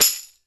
DrShake12.wav